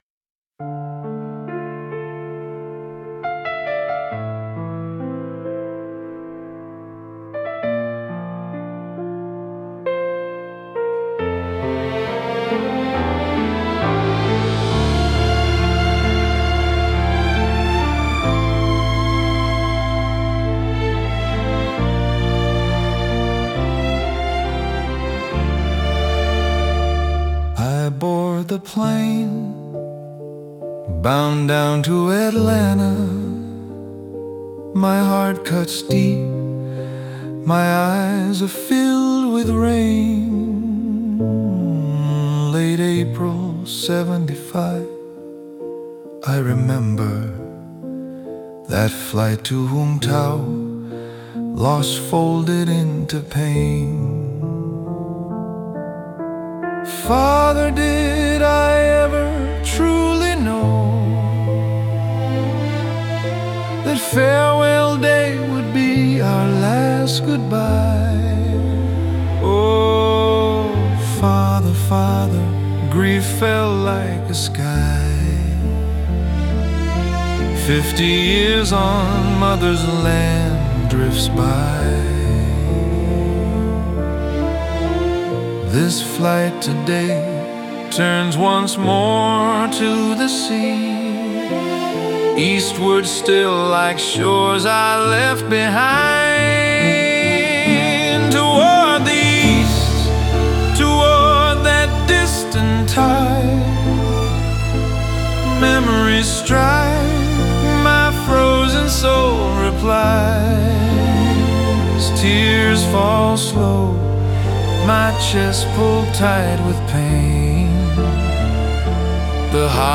English Song